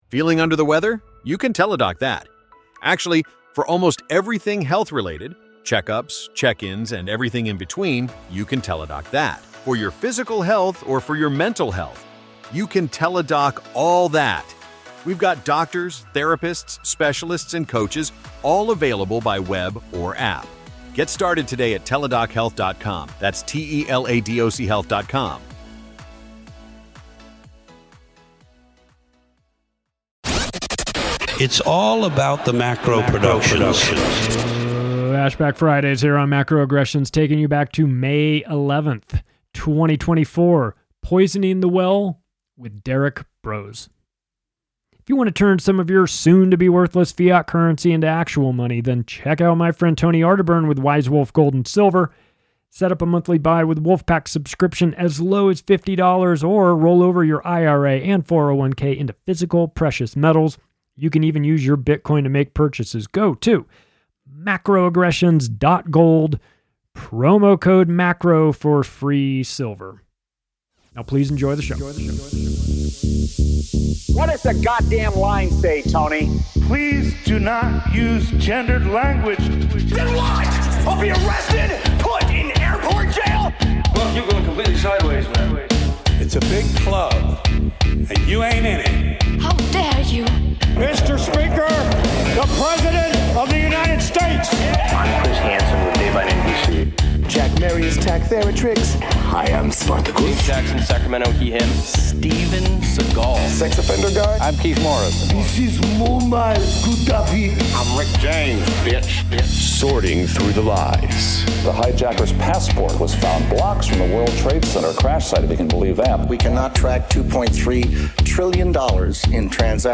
As of the recording of this interview, no verdict had been reached, but appeals will be flying from either side depending on the outcome.